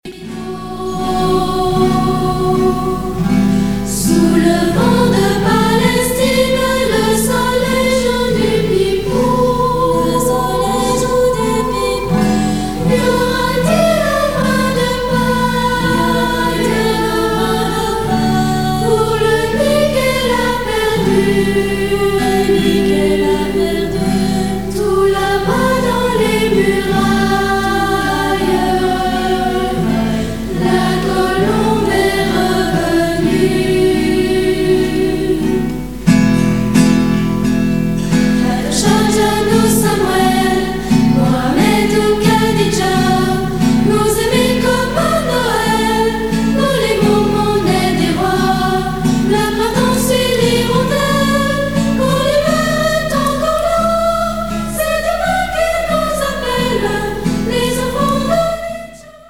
1987 - 1988 - Choeur d'enfants La Voix du Gibloux
On fête l’événement sous une cantine aménagée par le Football-Club. Les anciens unis aux plus jeunes